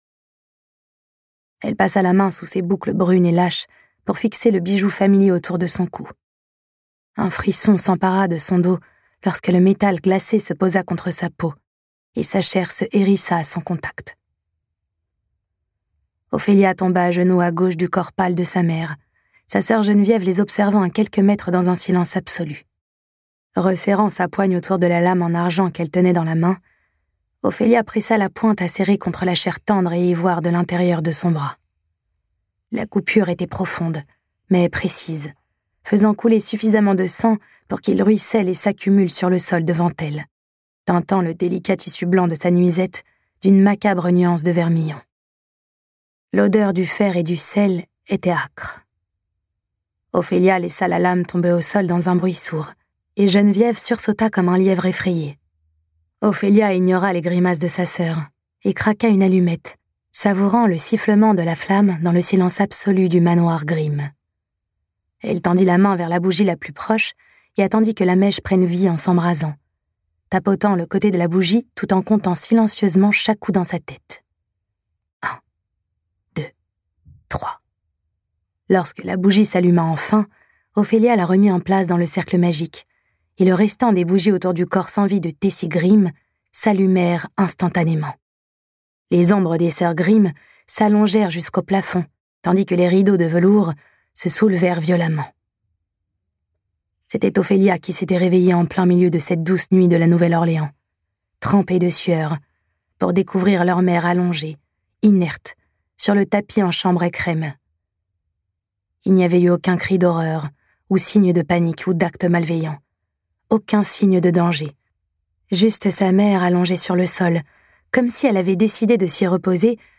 La voix profonde